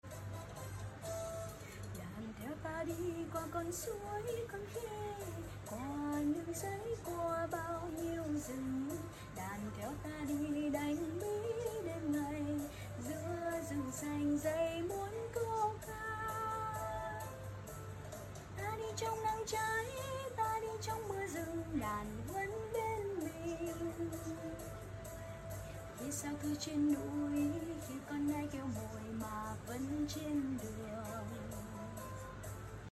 một bài hát gắn với cách mạng